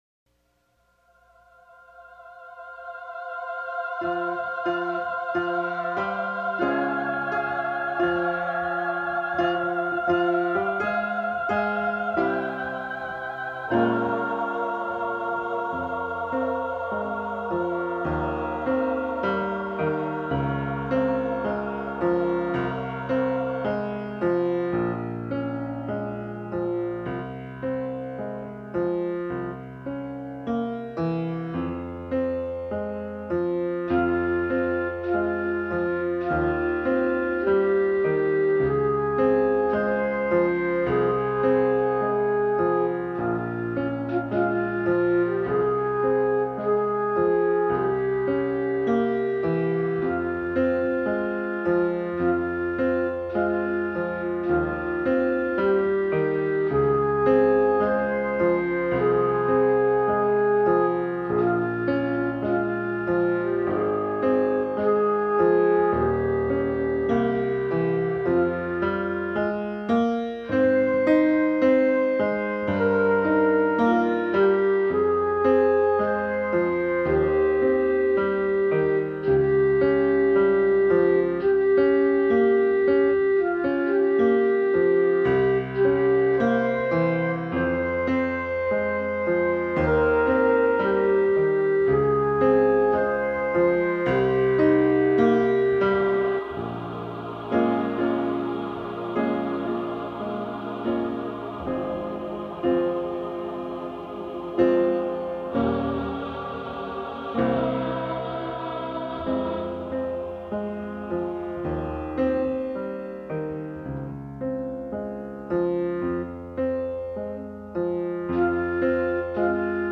This interpretation is a multitrack recording